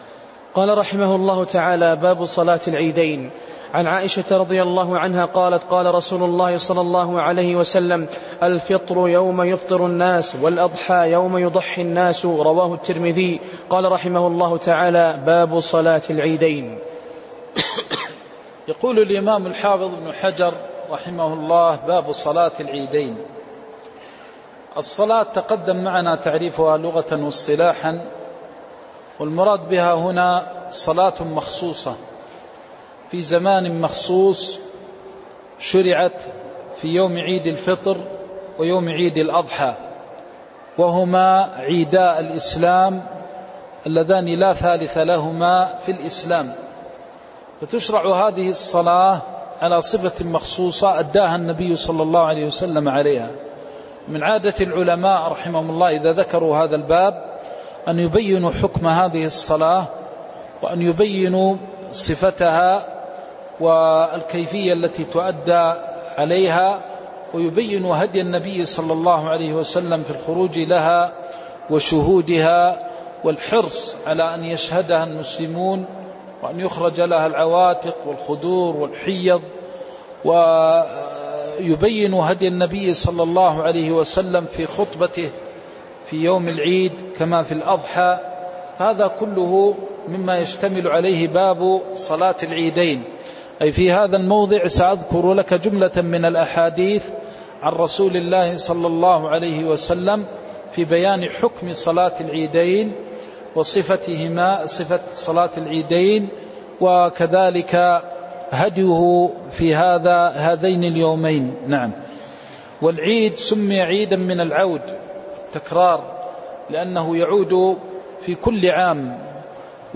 تاريخ النشر ٢٢ رمضان ١٤٤٠ المكان: المسجد النبوي الشيخ: فضيلة الشيخ د. محمد بن محمد المختار فضيلة الشيخ د. محمد بن محمد المختار باب صلاة العيدين